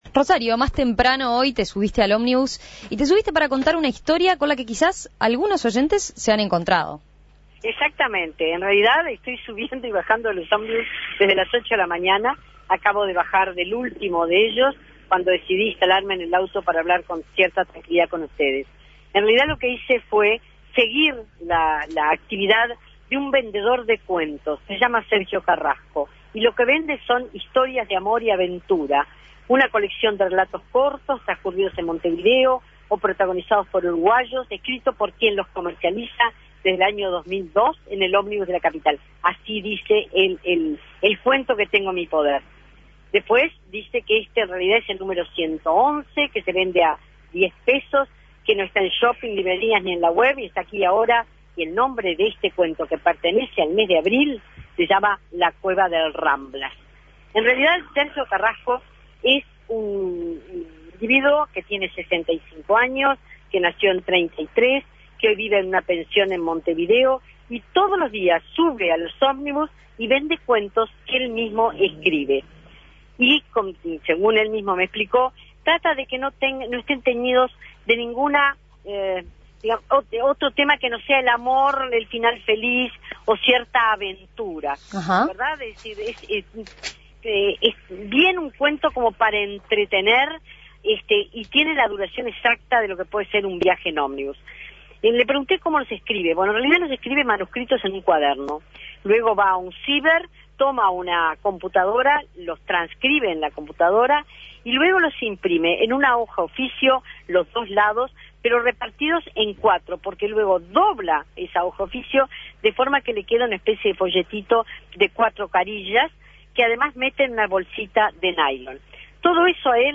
Cada día ofrece un cuento que él mismo escribió el día anterior y con eso se gana la vida. Con él viajamos hoy en uno de sus diarios trayectos